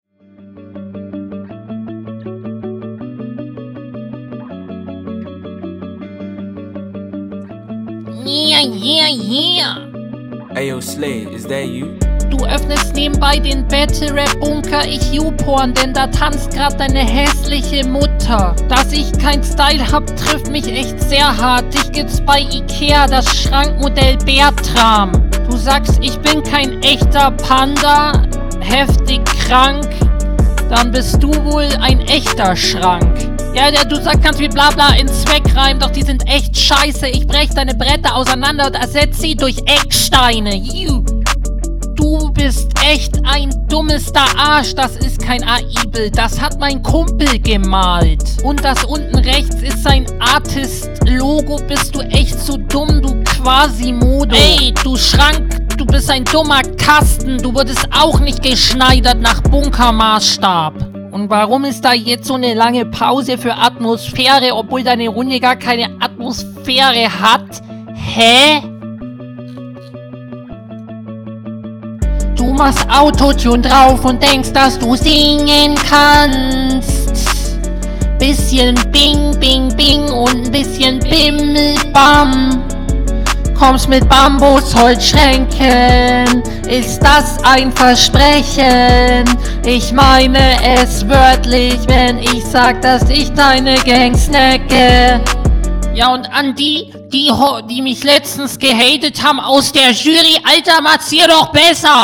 hört sich scheiße an digga. manchmal wunder ich mich wie man es hinbekommt so komisch …